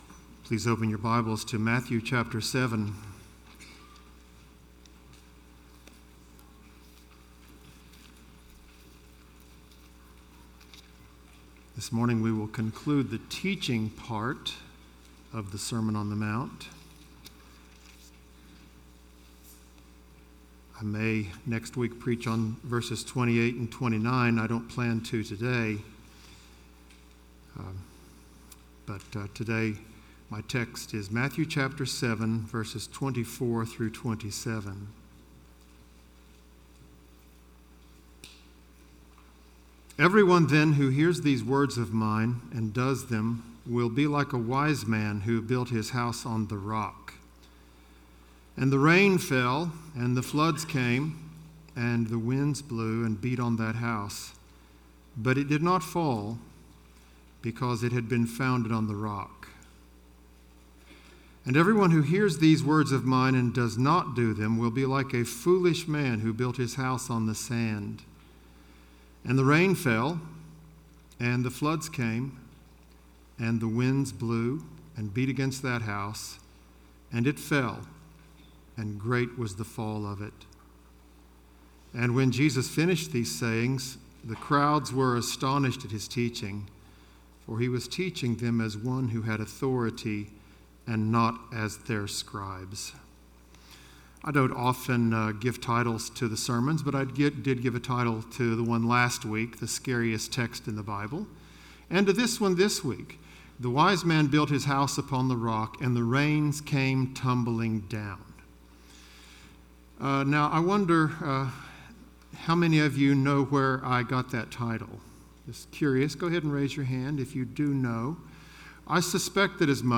Bullitt Lick Baptist Church - Sermons